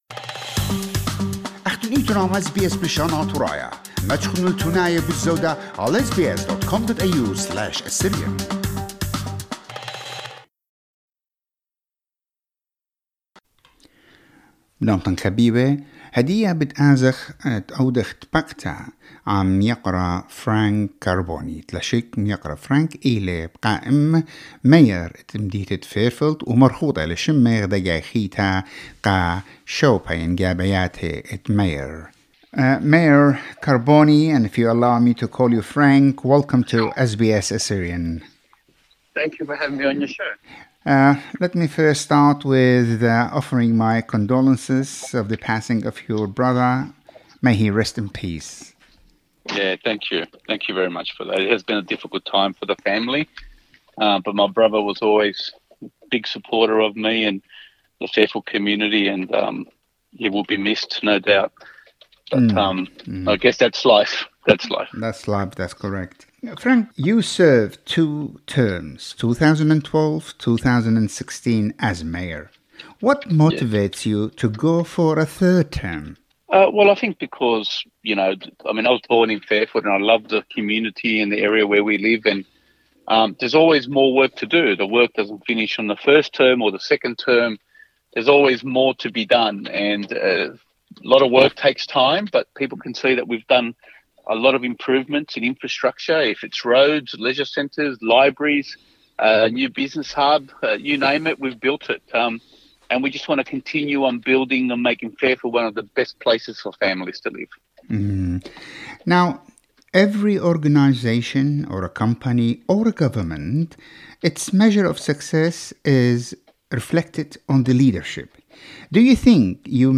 In this interview Mr Carbone outlines his achievements and work as a mayor in leading the council's team to achieve and complete many projects for the city of Fairfield. Mr Carbone says voters should vote for a mayor whom they believe will be the best for them to serve their needs. He says that people should not be influenced by a political party when making their decision who to vote for.